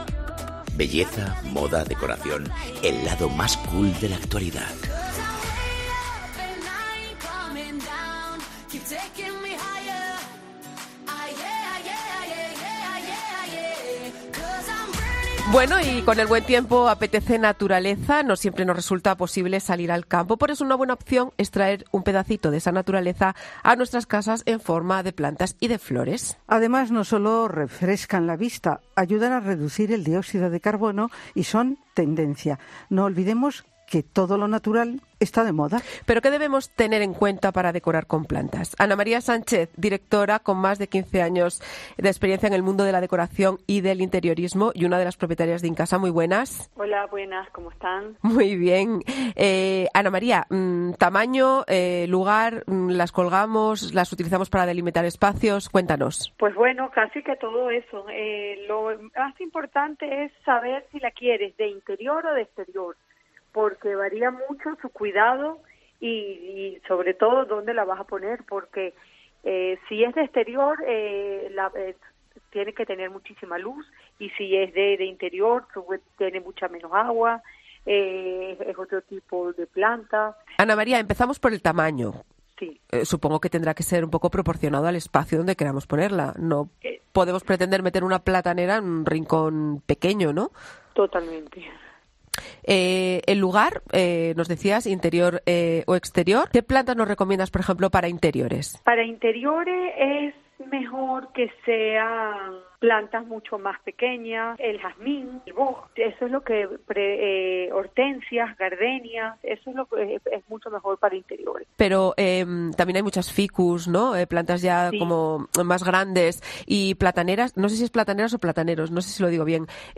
De momento puedes escuchar todos los consejos de la experta en nuestra SEXTA ENTREGA, pinchando AQUÍ